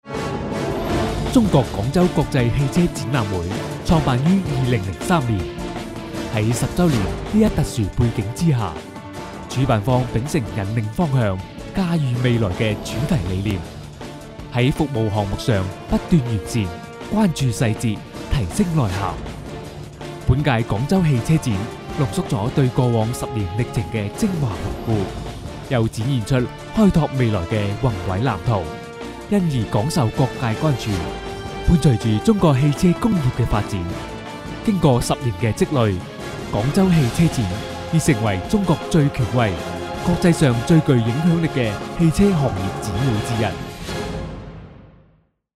男粤17_广告_促销_广州国际车展.mp3